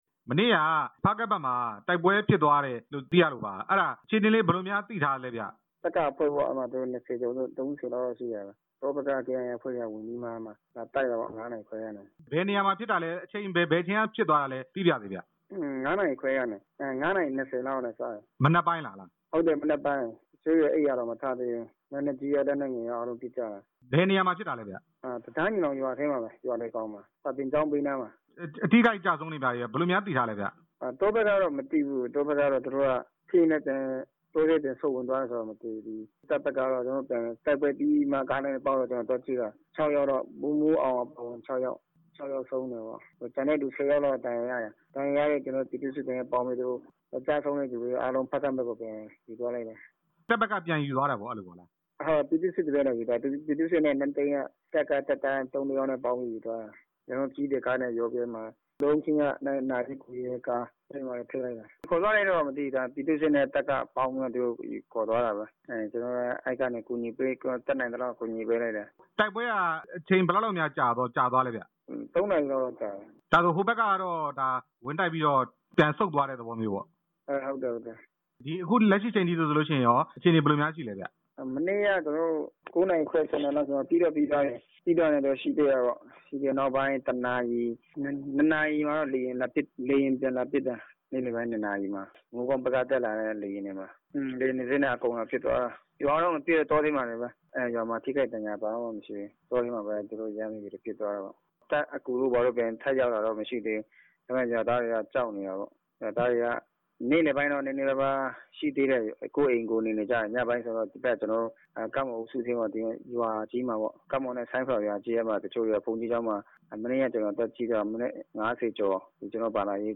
တပ်မတော်နဲ့ ကေအိုင်အေတို့ စစ်ရေးတင်းမာနေတဲ့အကြောင်း မေးမြန်းချက်